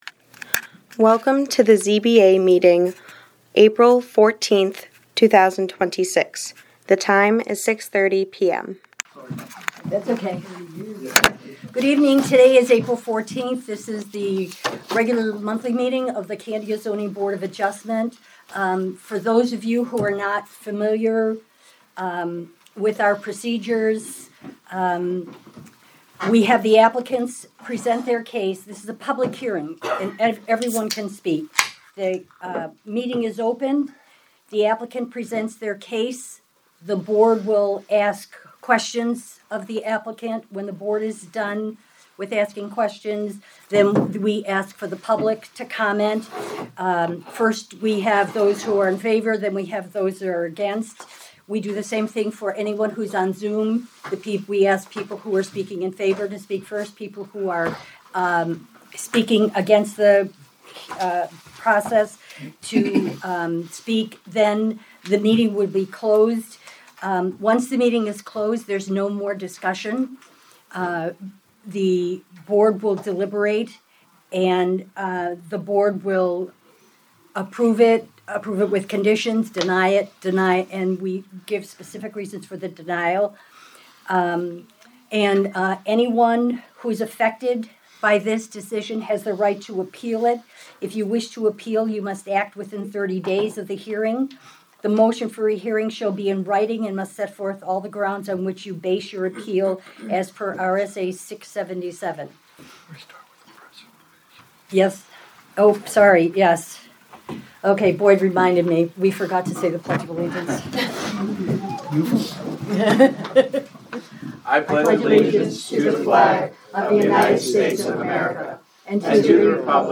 Audio recordings of committee and board meetings.
Zoning Board of Adjustment Meeting